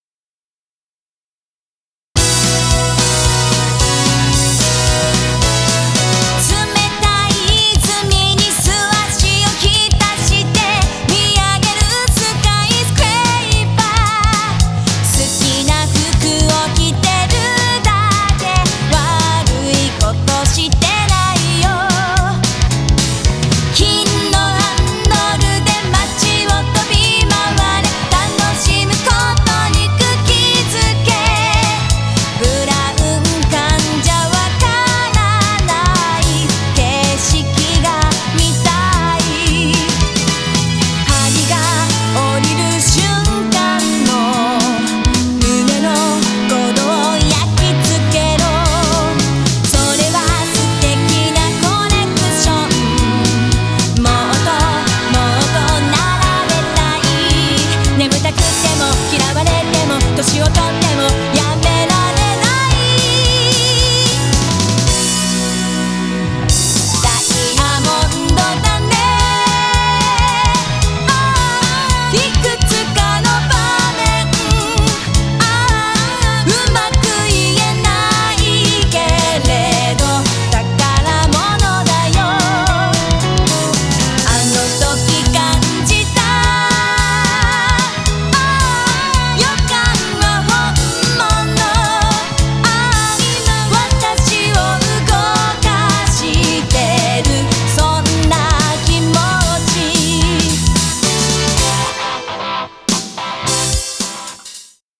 (J-POP)
BPM111
Audio QualityPerfect (High Quality)